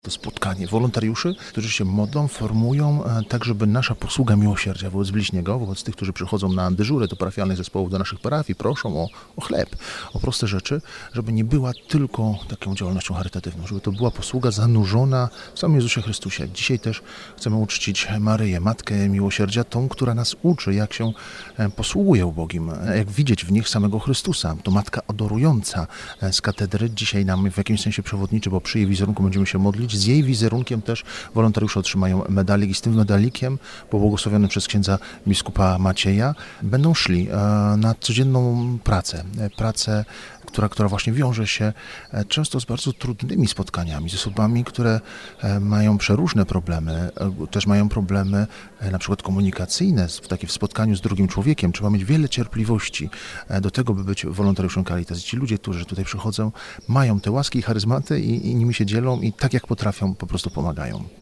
W sobotę o godzinie 10:00 rozpoczął się Zjazd Parafialnych Zespołów Caritas Archidiecezji Wrocławskiej.